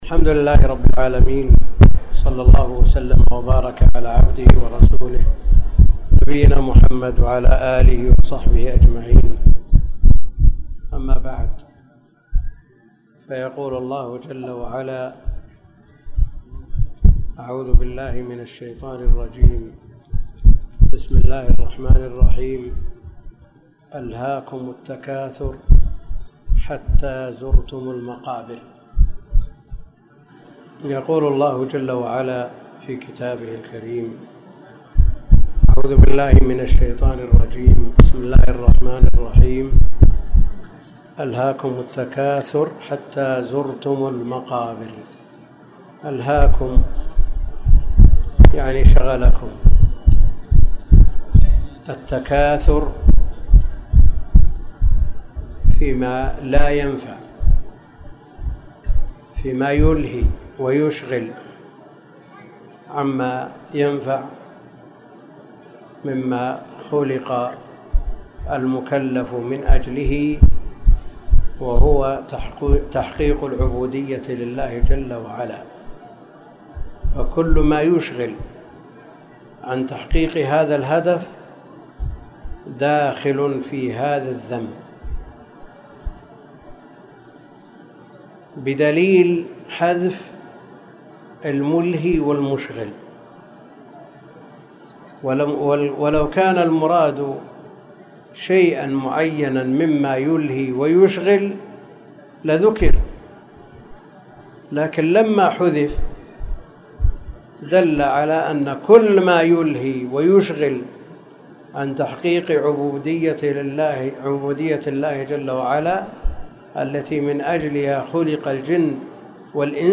محاضرة صوتية نافعة، وفيها تفسير سورة التكاثر، والتي هي